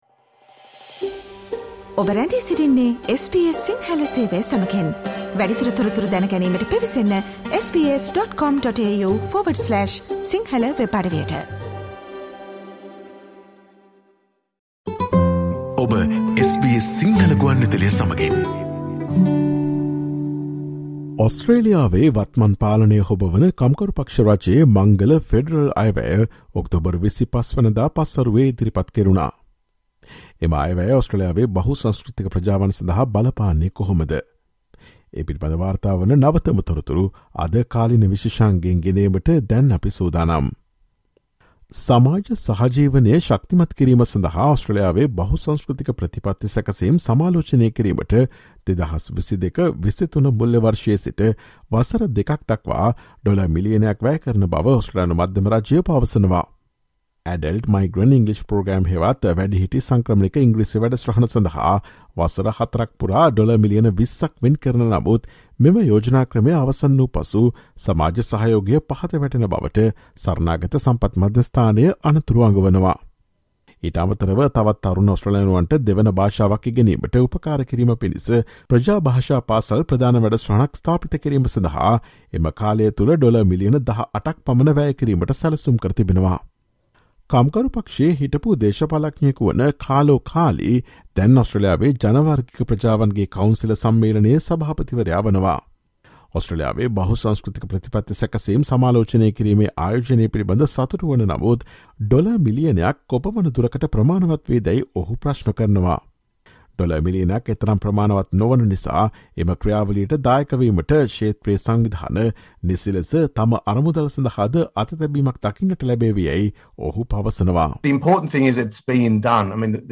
The Federal Labor Government has unveiled its first Federal Budget, so what is included for Australia's multicultural communities? Listen to the SBS Sinhala Radio's current affairs feature on Thursday 27 October.